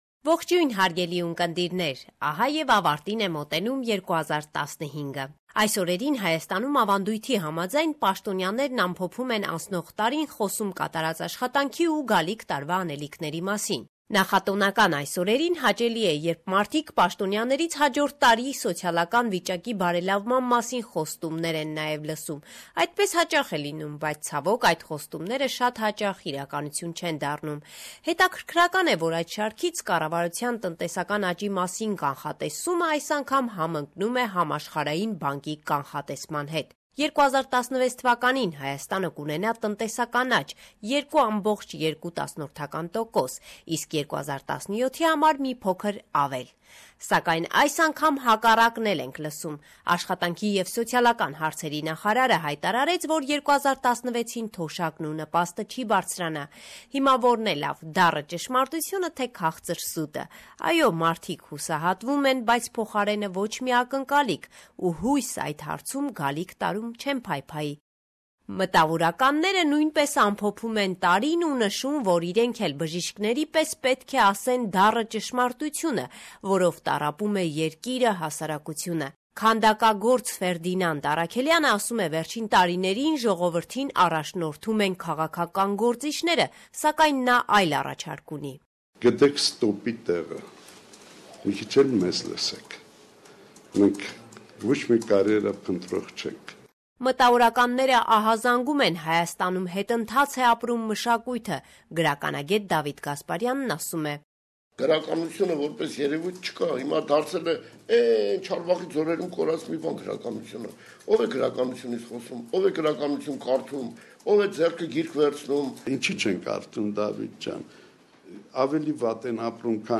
Latest News - 29/12/2015